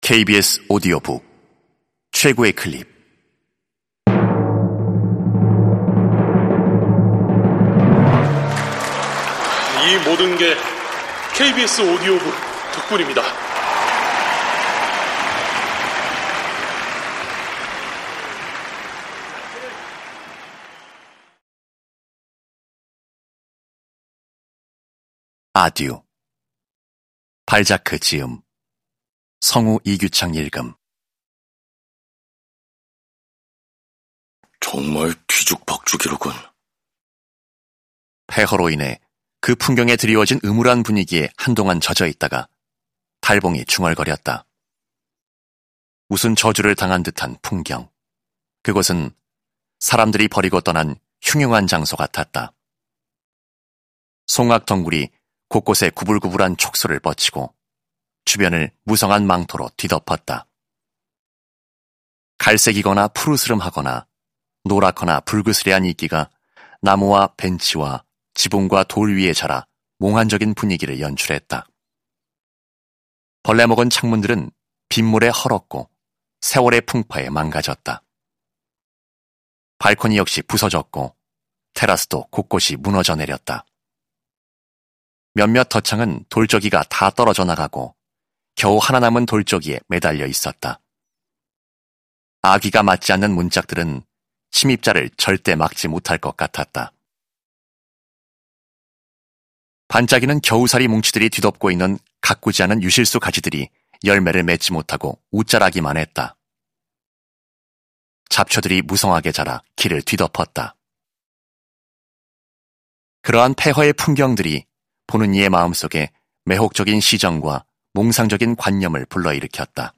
KBS 오디오북 - 최고의 클립